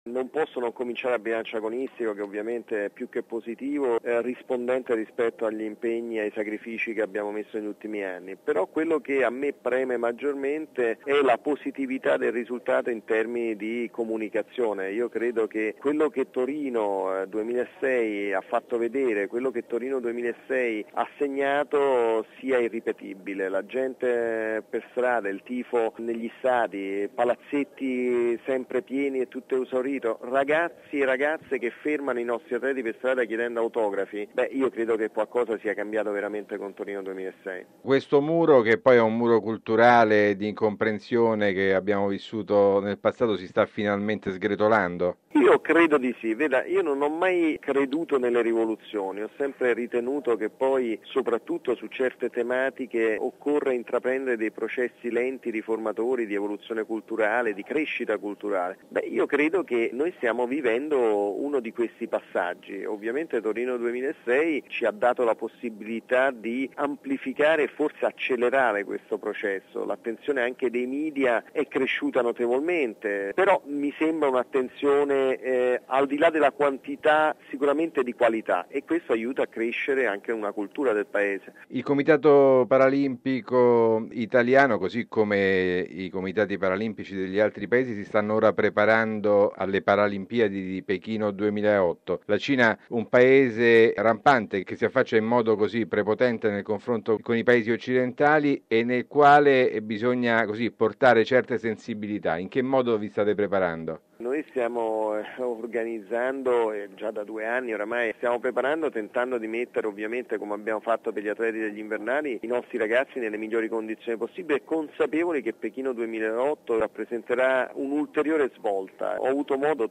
Per un bilancio globale dei Giochi, abbiamo sentito Luca Pancalli, presidente del Comitato paralimpico italiano: RealAudio